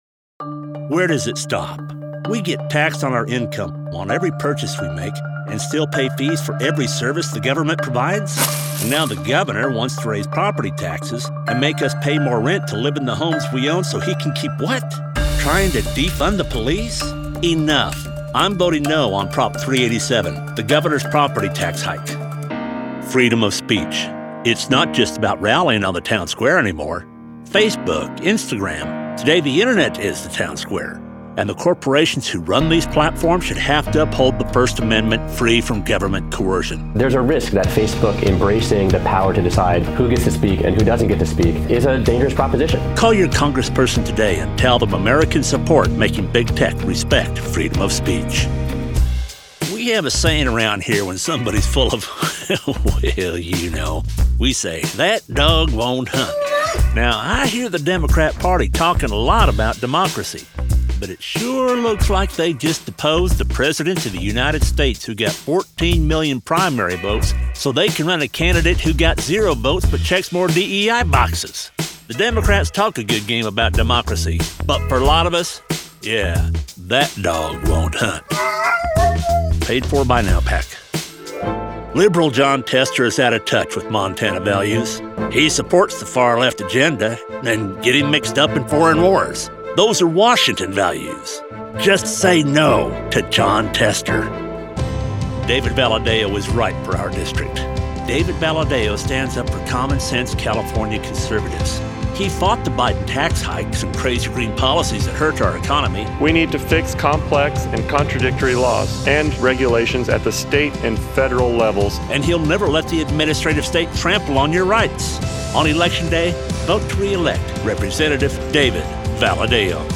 Political
English - Southern U.S. English
Western, Cowboy, Aviation, Pilot, Rugged, Authoritative, Trustworthy, Storytelling, Corporate, eLearning, Training, American, Mature, Deep, Confident, Experienced, Captain, Rodeo, Outdoor, Agriculture, Safety, Technical, Instructional, Reliable, Professional, Broadcast, Gritty, Grit, Gravel, Gravely, Commercials, Video games, Animation, Cartoon, Disney, Sam Elliott, Cinematic, Narration, Americana, Down-to-earth, Warm, Classic, Strong, Masculine, Character, Adventure, Frontier, Real, Western Villain, Drill Sargent, baritone, Upbeat, Happy, Conversational, teen, teenager, evil, manly, Rich, Resonant, Gravelly, Booming, Grizzled, Earthy, Smoky, Weathered, Matured, Full-bodied, Deep-toned, Bass, Low, Warmth, Textured, Frontiersman, Plainsman, Outlaw, wrangler, rancher